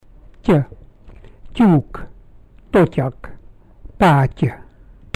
** The middle sound of  the word  "statue" is similar to "Ty"